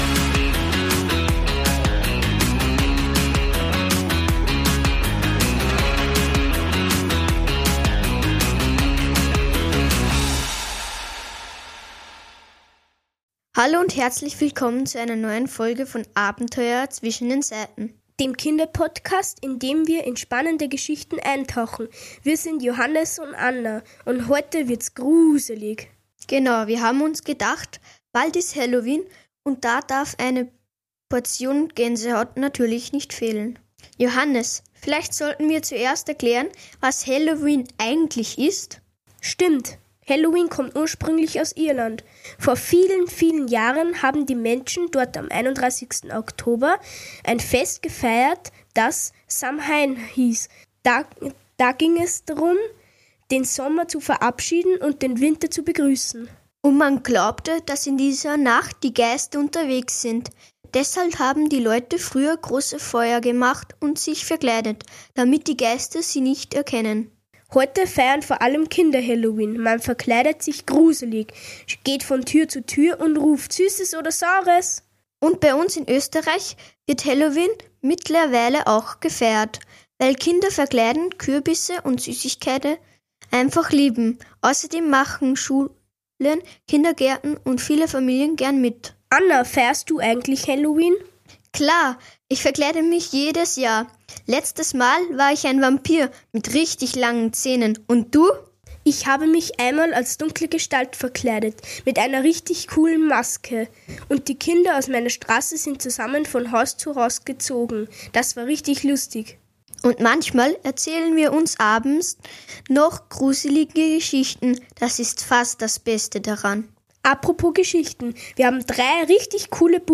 Heute wird geflüstert, gekichert und vielleicht ein